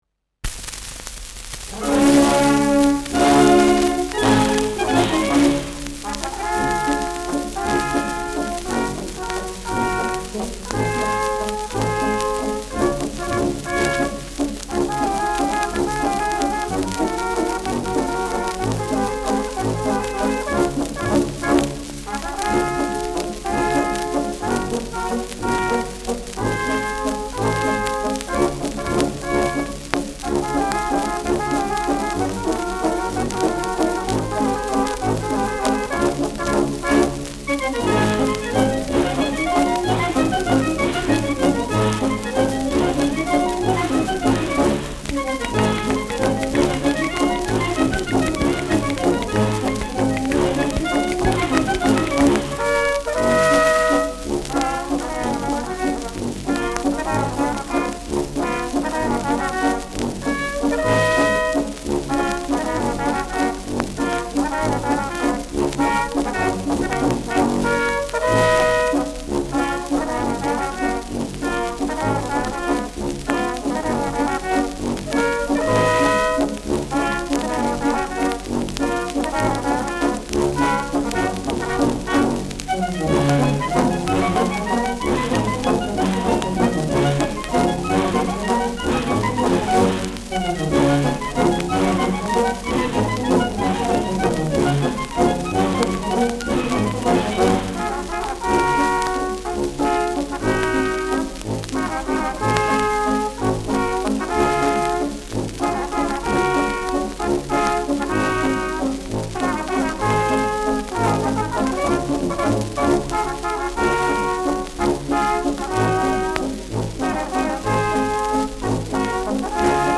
Schellackplatte
Mit Ausruf am Ende.
[Berlin] (Aufnahmeort)